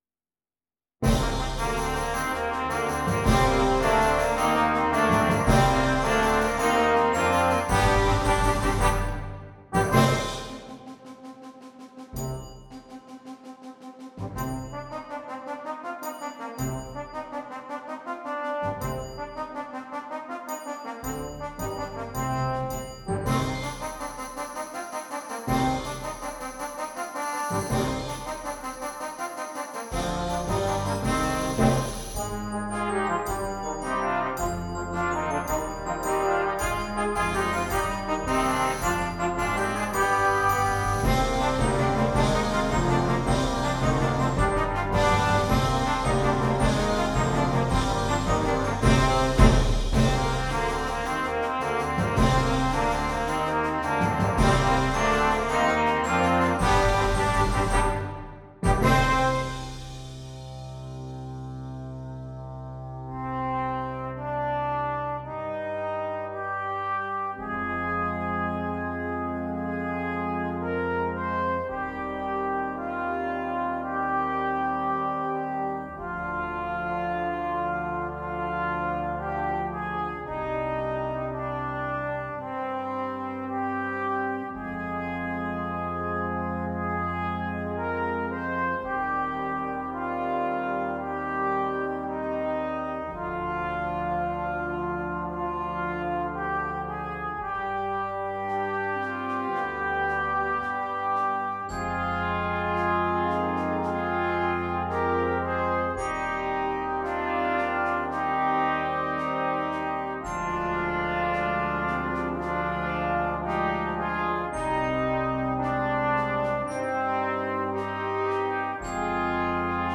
Brass Band
Difficulty: Easy Order Code
Flexible Junior Brass Band Series